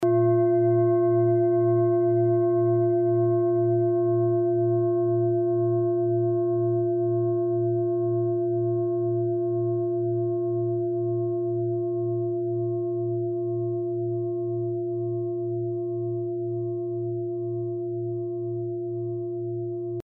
Klangschale TIBET Nr.46
Klangschale-Durchmesser: 29,0cm
Sie ist neu und ist gezielt nach altem 7-Metalle-Rezept in Handarbeit gezogen und gehämmert worden..
(Ermittelt mit dem Filzklöppel)
klangschale-tibet-46.mp3